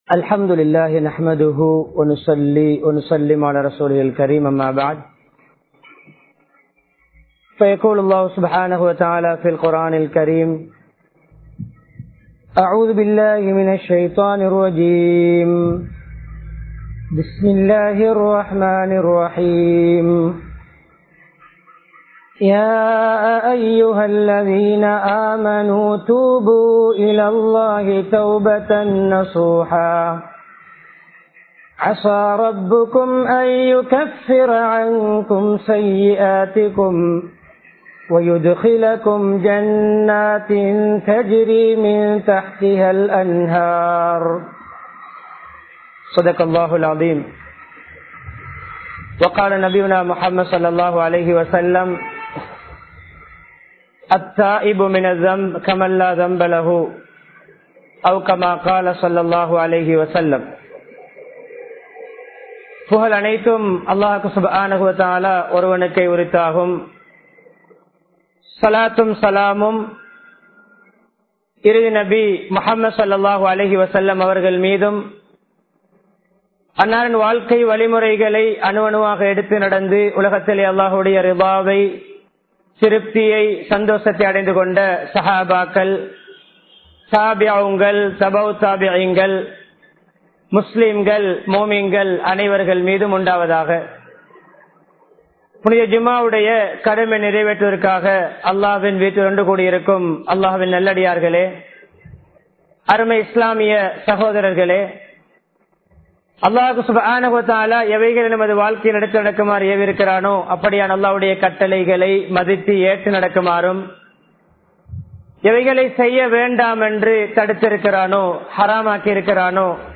தௌபா | Audio Bayans | All Ceylon Muslim Youth Community | Addalaichenai
Kawdana Road Jumua Masjidh